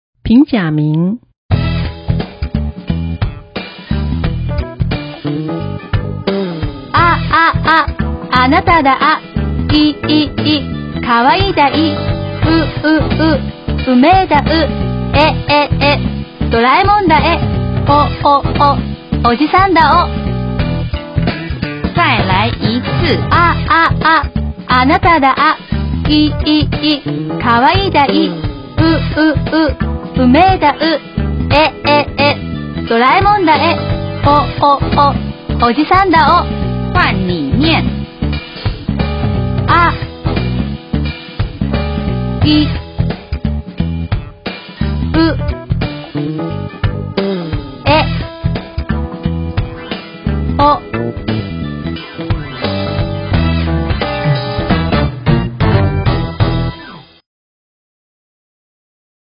☆跟無趣說再見！隨著RAP的節奏，一開口就能記住！
無論是開車、坐車、等車，或是洗澡、做家事時，都能利用碎片時間把日語學到家，一開口就是那麼原汁原味的東京口音！
50音RAP記憶法